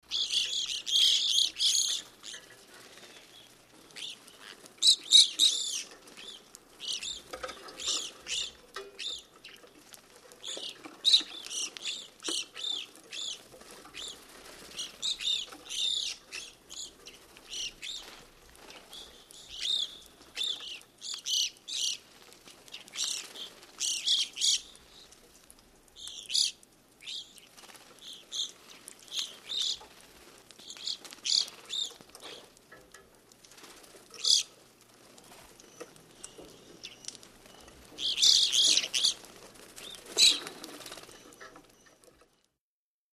Evening grossbeaks, New York State